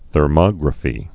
(thər-mŏgrə-fē)